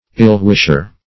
Ill-wisher \Ill`-wish"er\, n. One who wishes ill to another; an enemy.